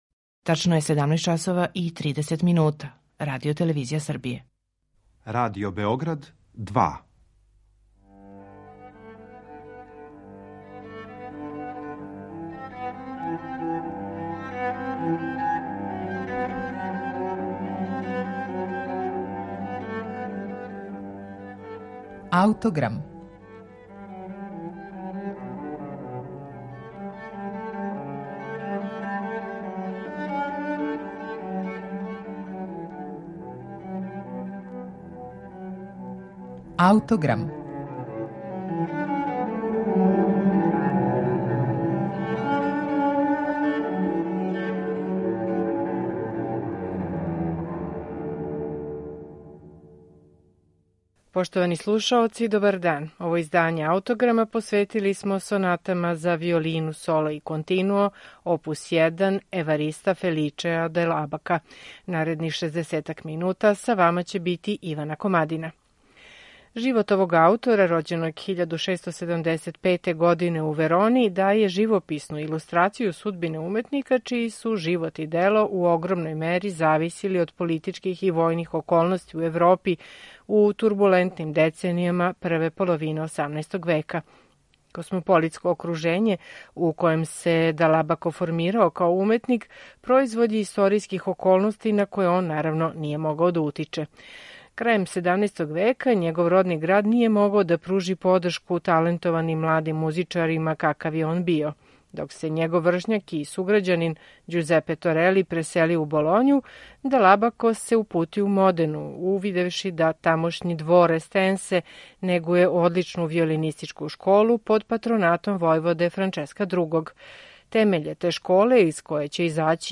У данашњем Аутограму представићемо Дал Абакову збирку камерних соната за виолину и басо континуо опус 1.